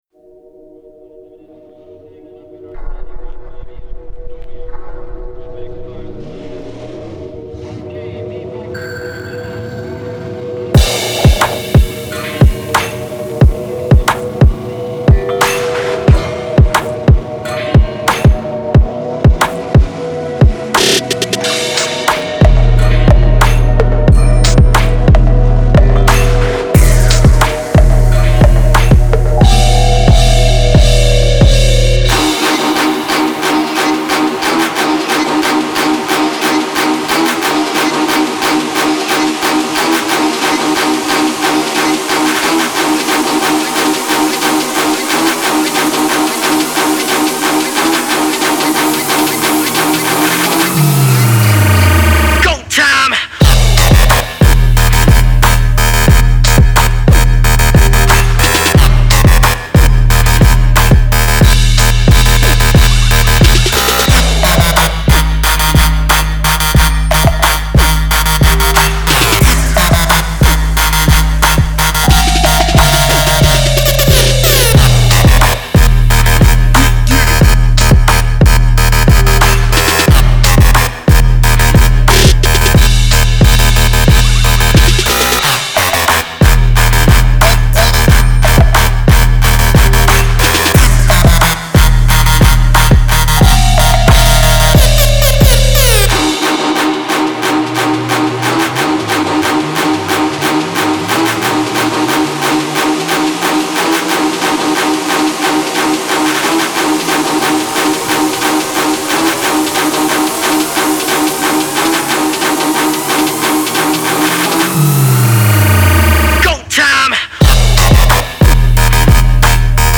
muzika_v_mashinu_bass____bassi_na_polnuju_6_.mp3